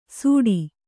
♪ sūḍi